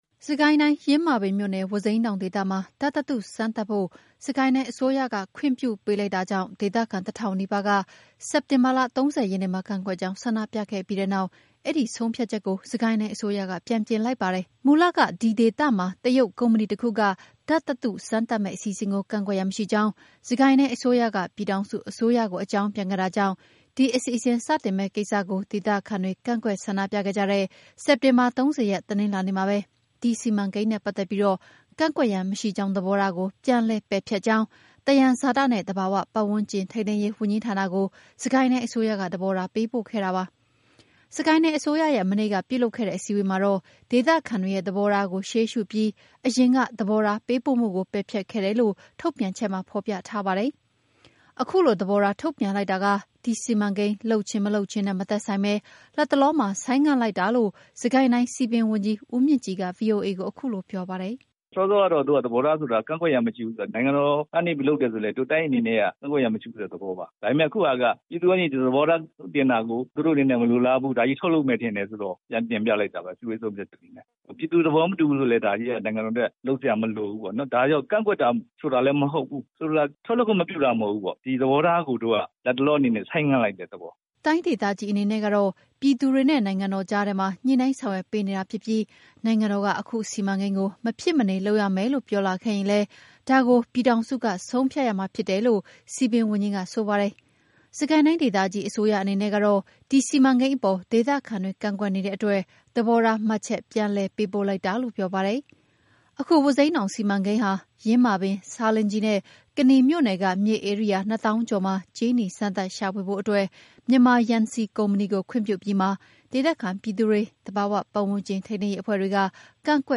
စစ်ကိုင်းတိုင်းအစိုးရရဲ့ မနေ့ကပြုလုပ်တဲ့အစည်းအဝေးမှာ ဒေသခံတွေရဲ့သဘောထားကိုရှေးရှုပြီး အရင်ကသဘောထားပေးပို့မှုကို ပယ်ဖျက်ခဲ့တယ်လို့ ထုတ်ပြန်ချက်မှာ ဖော်ပြထားပါတယ်။ အခုလိုသဘောထားထုတ်ပြန်လိုက်တာက ဒီစီမံကိန်းလုပ်ခြင်း၊ မလုပ်ခြင်းနဲ့မသက်ဆိုင်ဘဲ လတ်တလောမှာ ဆိုင်းငံ့လိုက်တာလို့ စစ်ကိုင်းတိုင်းစည်ပင်ဝန်ကြီး ဦးမြင့်ကြည်က ဗွီအိုအေကို အခုလို ပြောပါတယ်။